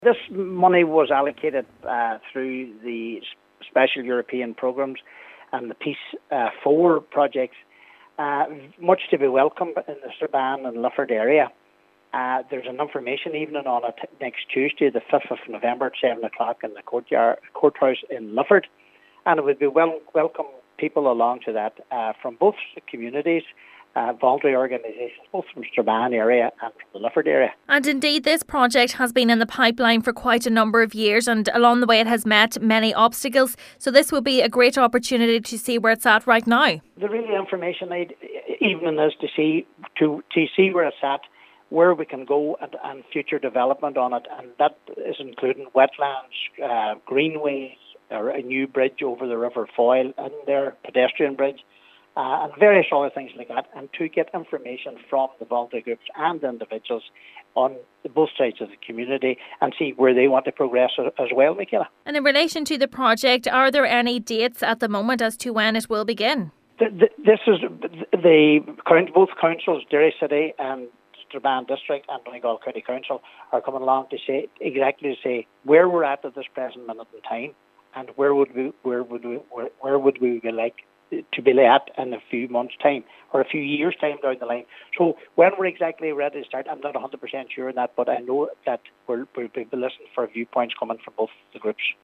Cathaoirleach of Donegal County Council Councillor Nicholas Crossan says the meeting is an opportunity for people to be updated on the progress of the project: